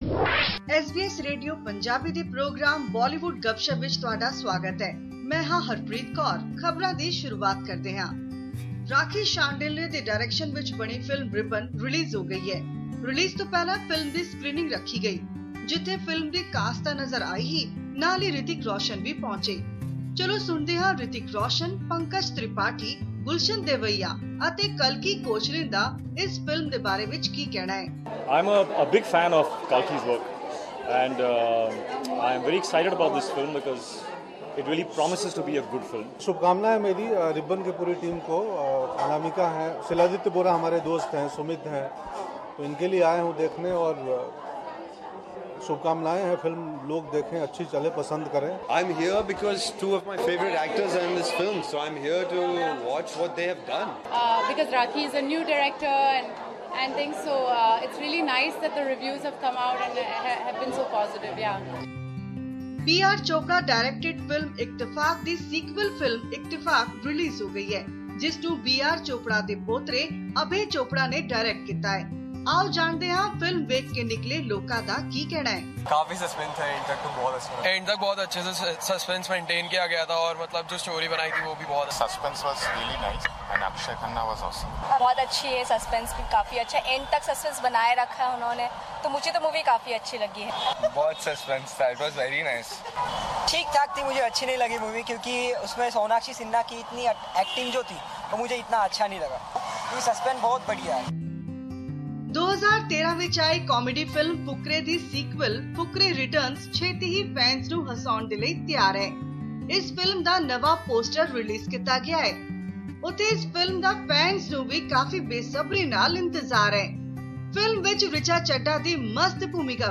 know about latest upcoming movies with clips of songs and dialogs.